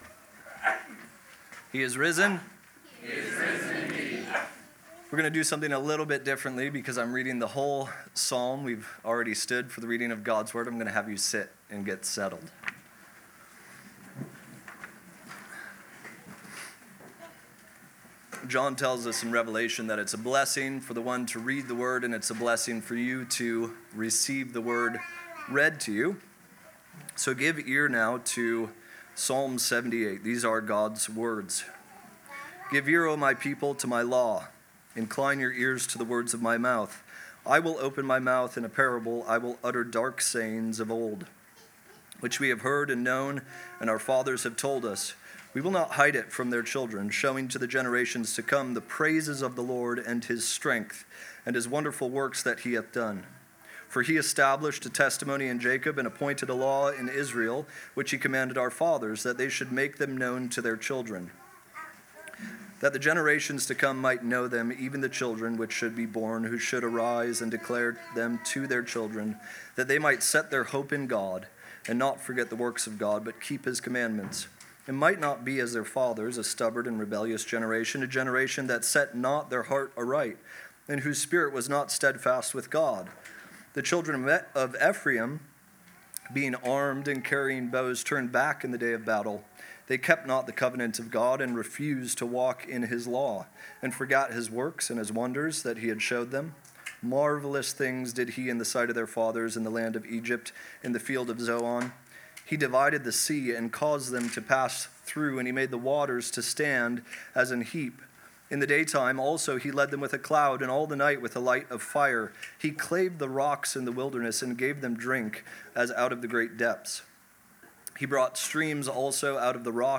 Back to Sermons